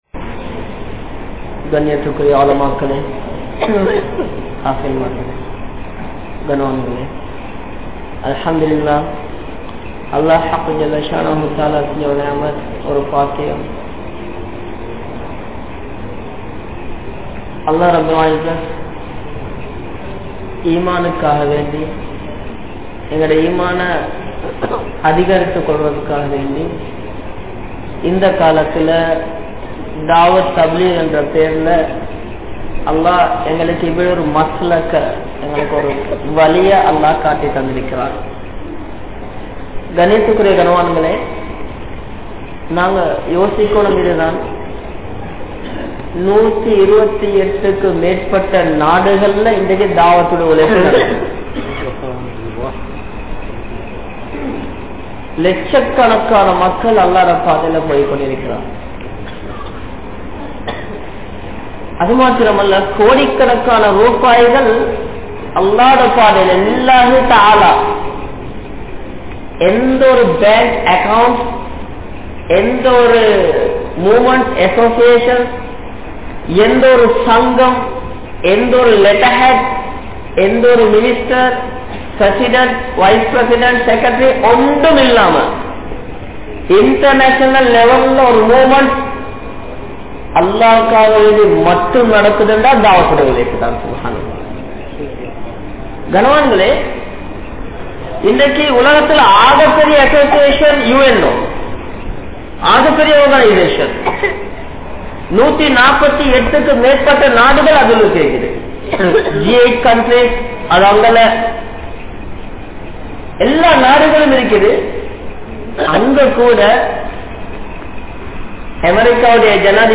Manithanin Indraya Nilamai | Audio Bayans | All Ceylon Muslim Youth Community | Addalaichenai